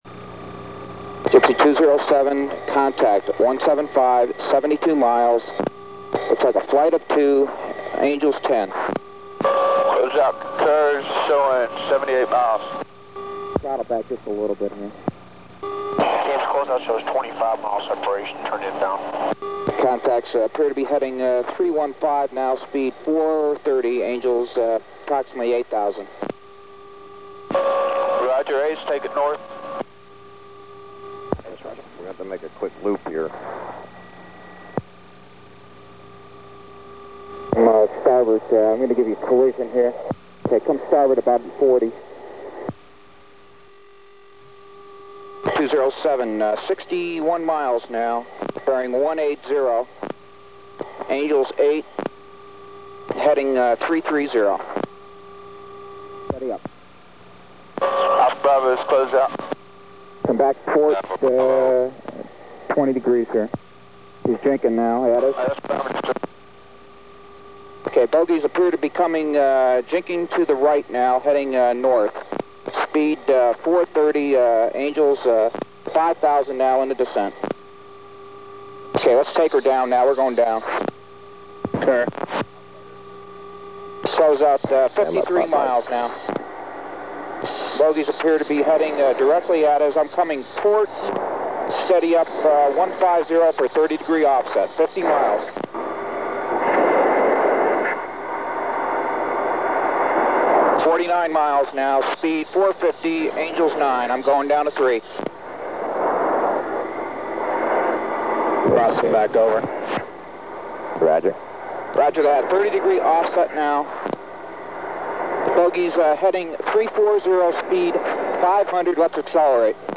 Quel file è molto interessante (anche se un po' vecchiotto) si capiscono bene tutte le comunicazioni usate durante l' ingaggio, se vuoi ne ho altri (anche + moderni).